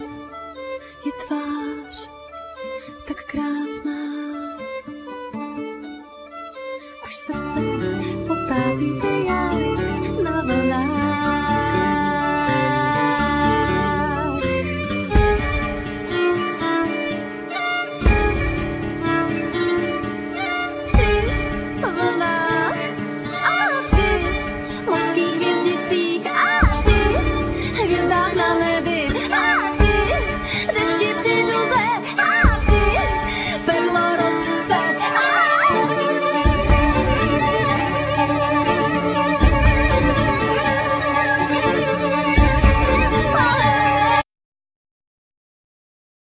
Violin,Kalimba,Percussion,Viola,Vocal,Africanlyra
Guitar,Sas,Vocal,Percussion,Mandolin
Cello
Trumpet
Double bass